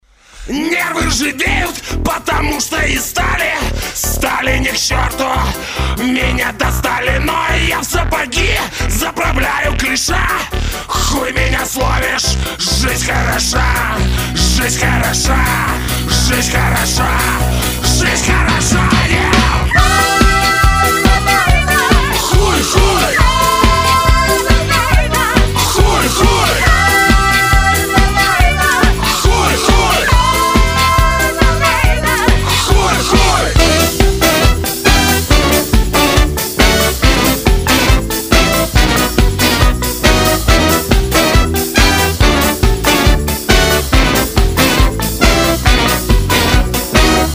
Нарезка на звонок